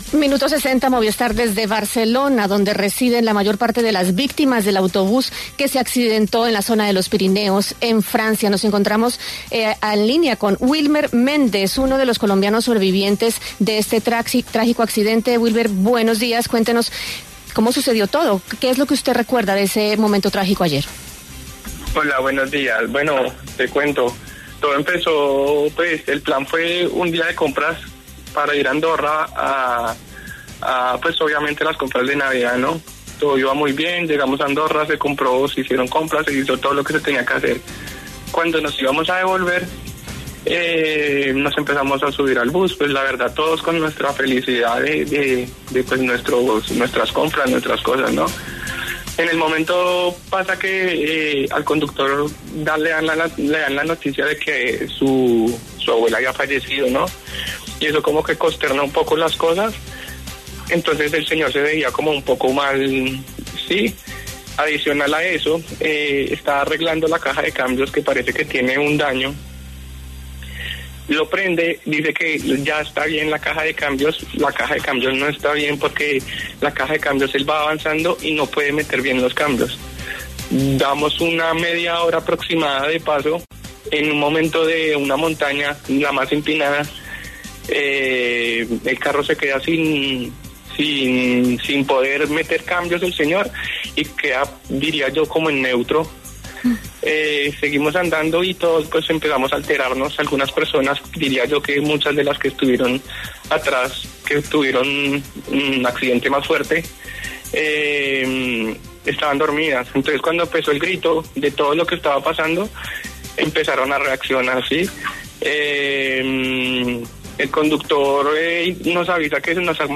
Uno de los colombianos que iba al interior del bus que se accidentó en Francia contó en La W cómo ocurrió el accidente.